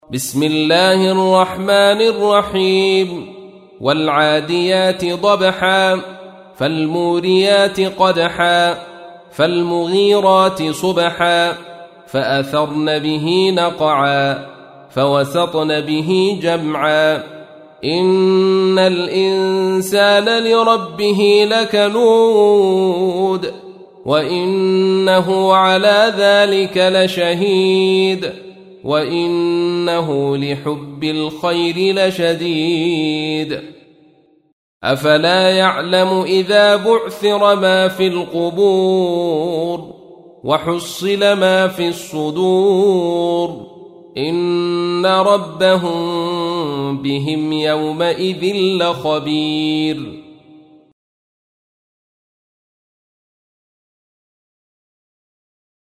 تحميل : 100. سورة العاديات / القارئ عبد الرشيد صوفي / القرآن الكريم / موقع يا حسين